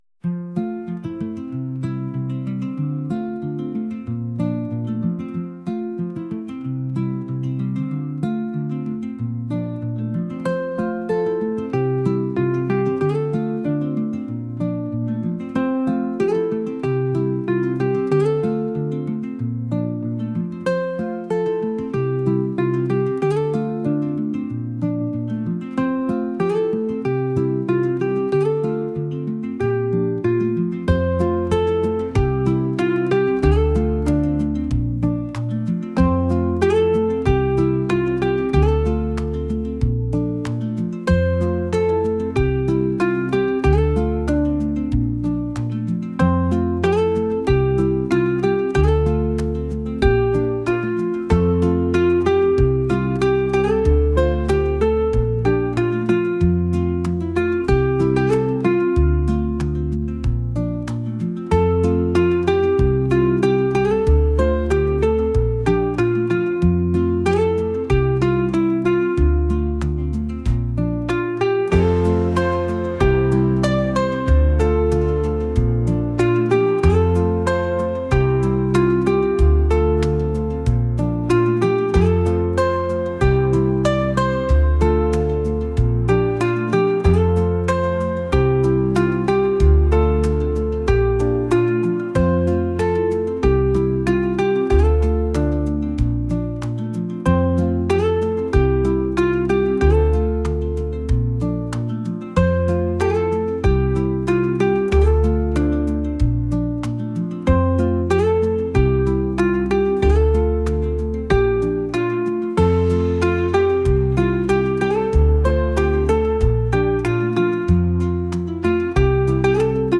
indie | pop | folk